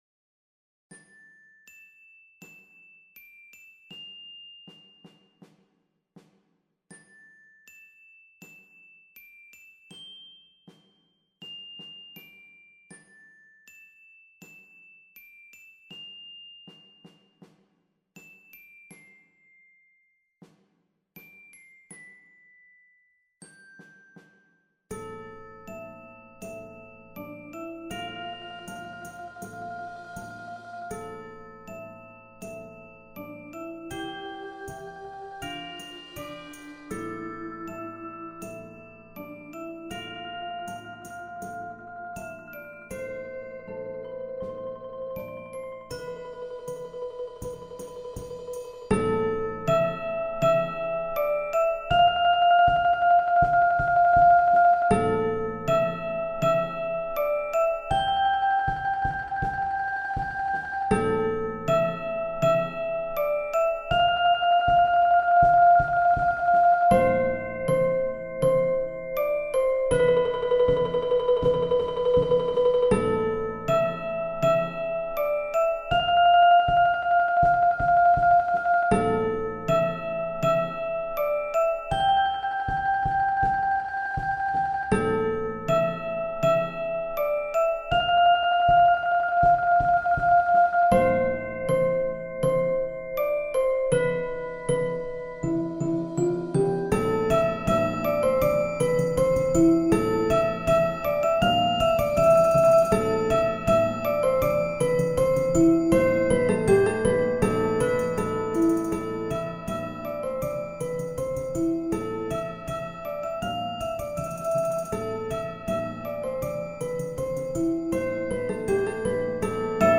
Genre: Flex Percussion Ensemble
Xylophone
Vibraphone*
Chimes
Marimba 1 [4-octave]
Timpani [2 drums]
Snare Drum*
Bass Drum
Suspended Cymbal
2 Concert Toms